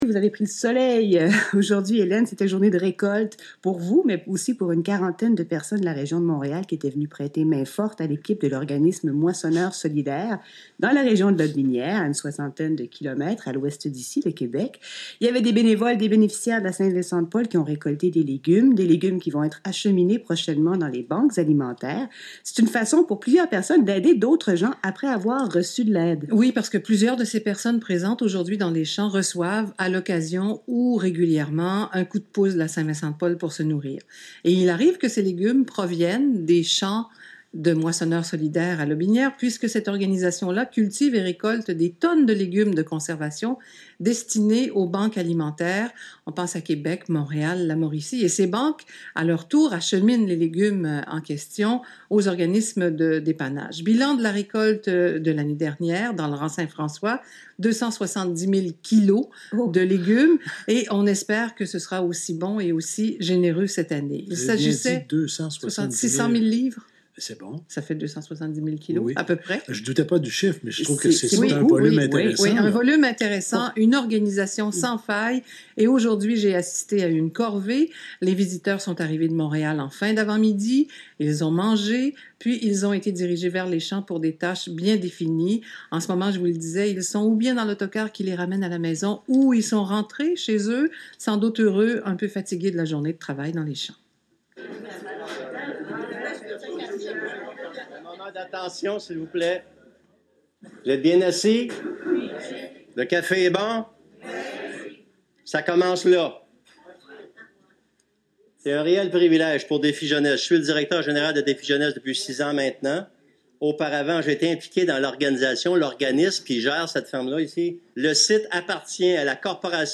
Entrevue Bien dans son Assiette.
Entrevue-Bien-dans-son-Assiette.mp3